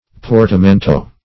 Search Result for " portamento" : The Collaborative International Dictionary of English v.0.48: Portamento \Por`ta*men"to\, n. [It., fr. portare to carry.]